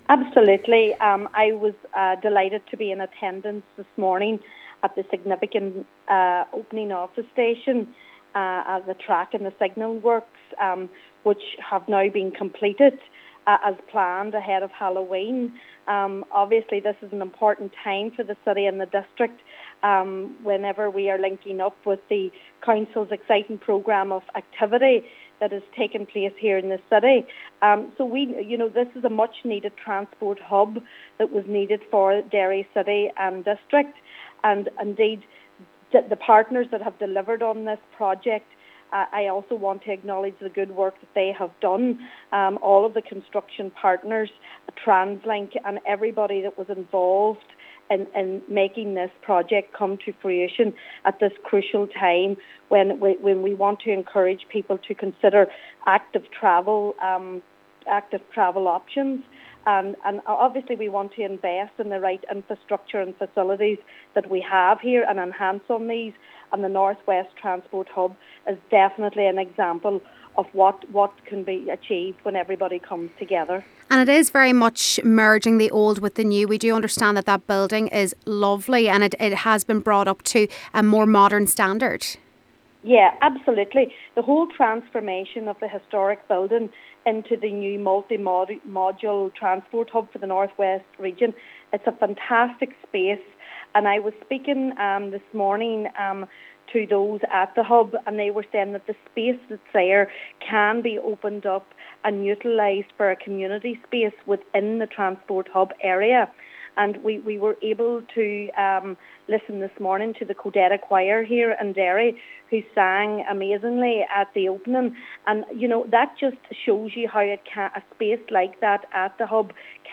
Mayor of Derry City and Strabane District Council Michaela Boyle says is a fantastic asset to the entire North West Region: